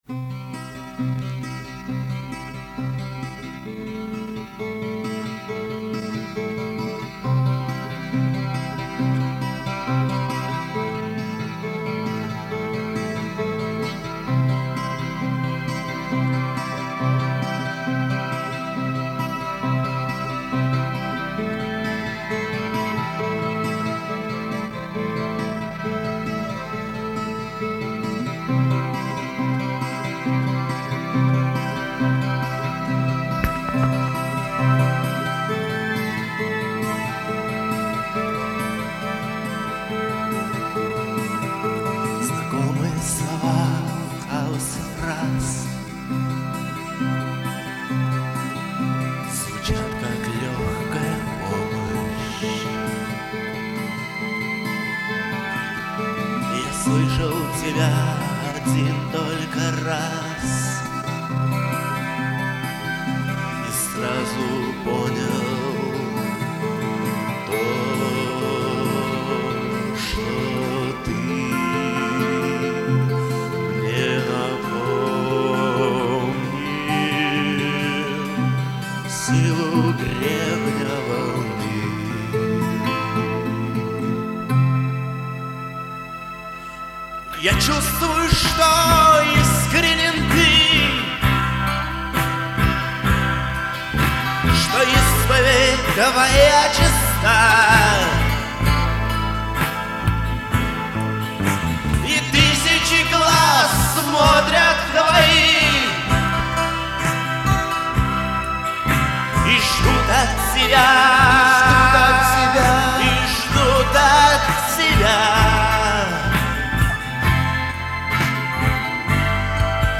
Сборник пробных записей, этюдов, репетиций
музыка, текст, вокал, гитары
барабаны, перкуссия, драм-машина
бэк-вокал, бас-гитара
клавиши (фортепиано, vermona, электроника)